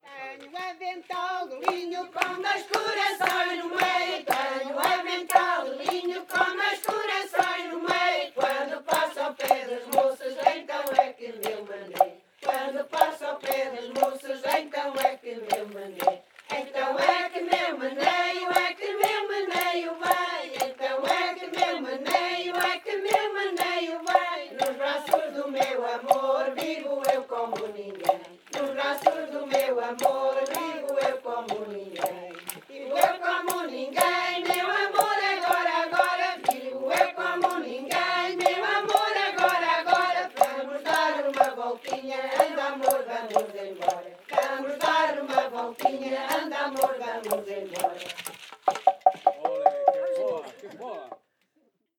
Grupo Etnográfico de Trajes e Cantares do Linho
Tenho um avental de linho (versão 1) (Várzea de Calde, Viseu)